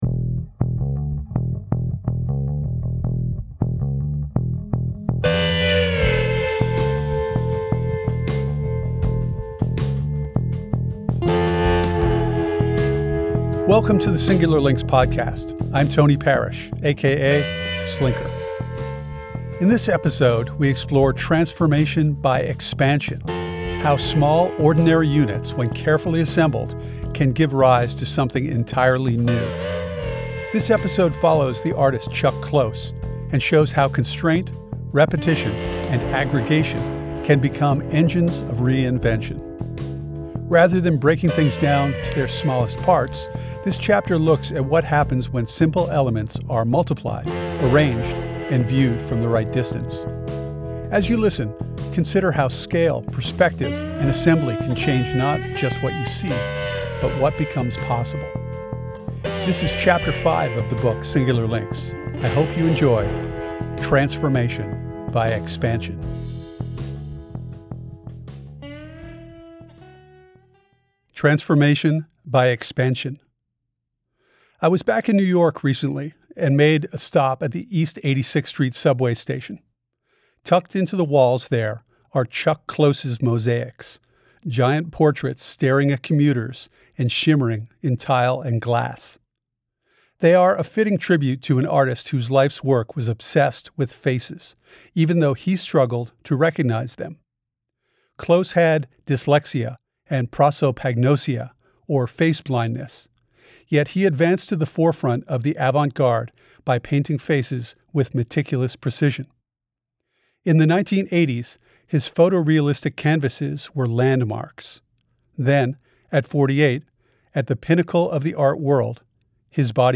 This episode features Chapter Five of the audio book Singular Links.